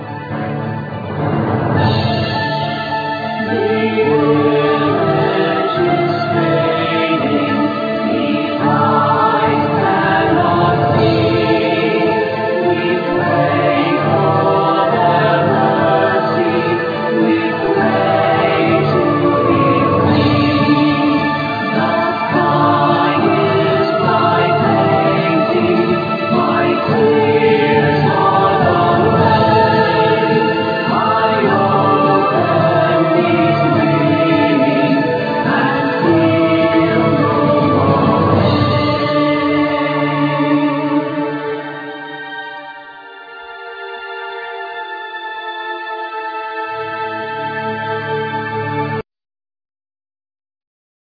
All instruments,Voice
Voice